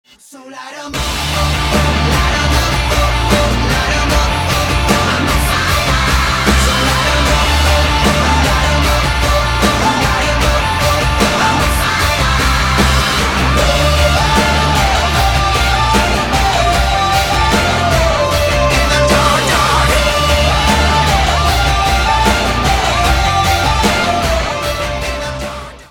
• Качество: 256, Stereo
ритмичные
громкие
Драйвовые
заводные
Alternative Rock
indie rock
dance-rock